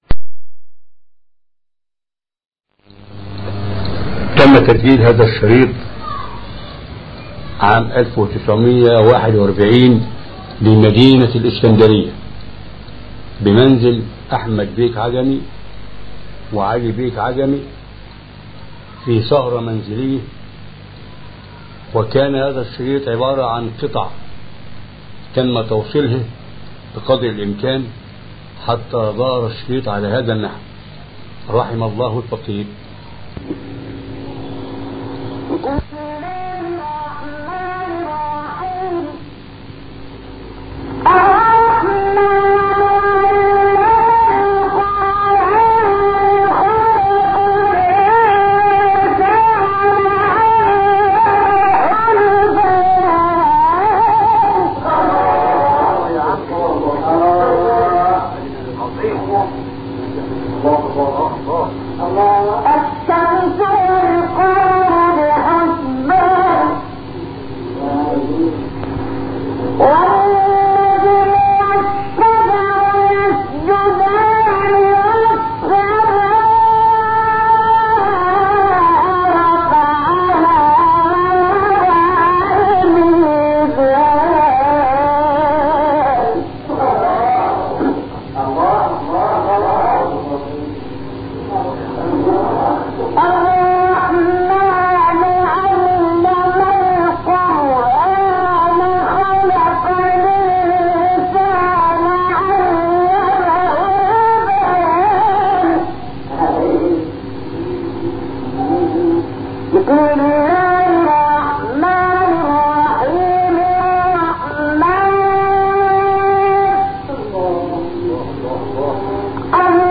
این تلاوت در شهر اسکندریه مصر و در سال 1941 میلادی اجرا شده است.